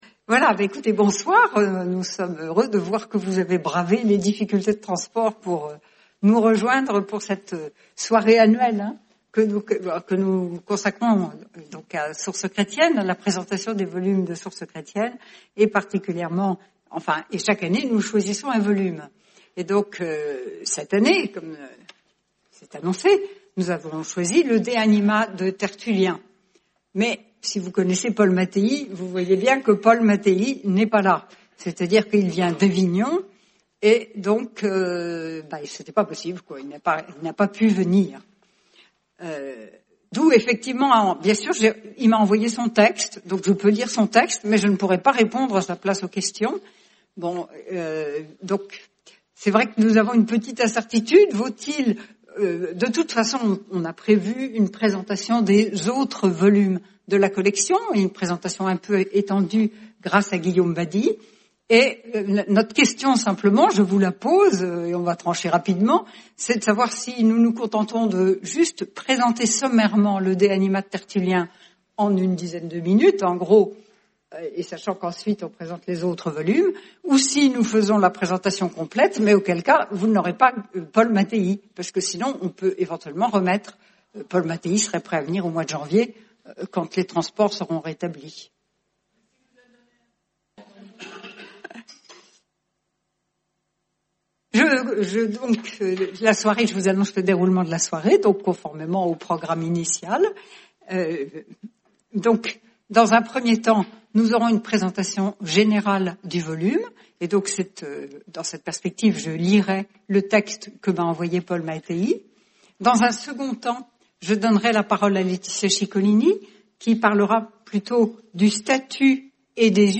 Soirée du 11 décembre 2019, avec l’Institut des Sources Chrétiennes.